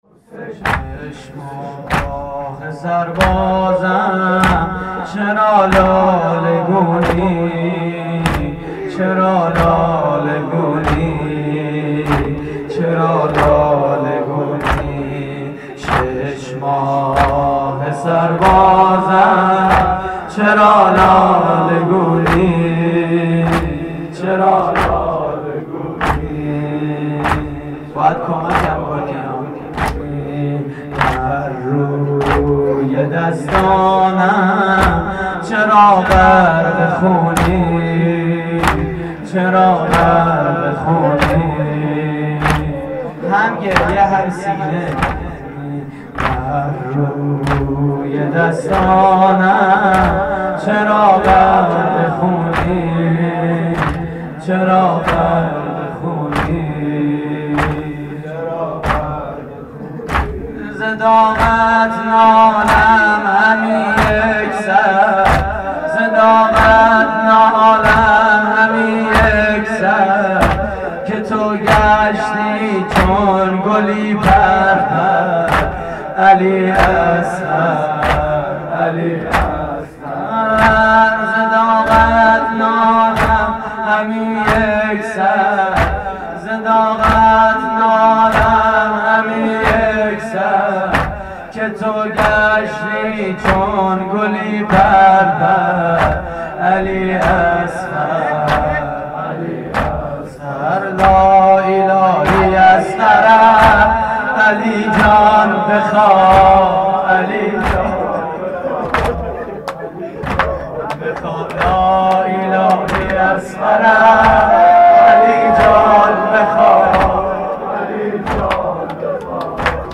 مراسم عزاداری شب هفتم محرم 1432 / هیئت کریم آل طاها (ع) – عبدل آباد؛ 11 آذر 1390